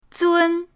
zun1.mp3